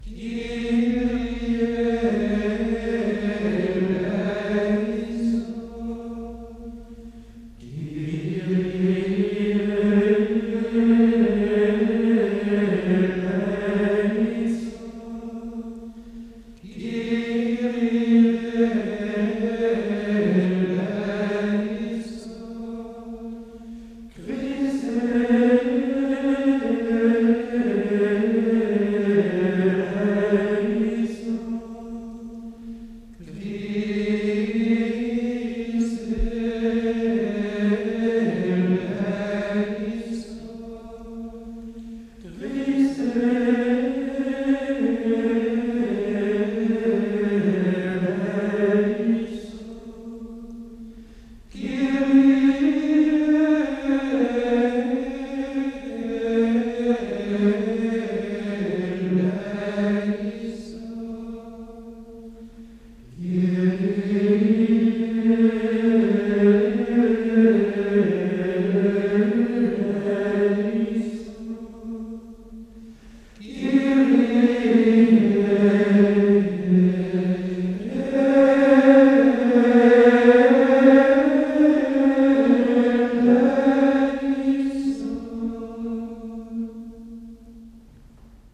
Nous sommes en présence d’une mélodie très simple, assez restreinte, si bien que ce Kyrie tranche un peu avec les plus somptueux.
Il est marqué en 4 mode, de fait toutes les cadences finales des neuf invocations se font entendre sur le Si, c’est-à-dire un Mi transposé à la quinte.
La ligne mélodique générale rend un bel effet de crescendo, en s’élevant progressivement, jusqu’au eléison final très expressif et ardent.
Le deuxième Kyrie part du Mi grave, et de cette belle profondeur, remonte progressivement vers le Si, avec un eléison identique au précédent.
Enfin, le tout dernier Kyrie part du Ré aigu, mais descend donnant l’effet d’une belle supplication.
Abbaye-Saint-Pierre-de-Solesmes-France-Kyrie-XV-.mp3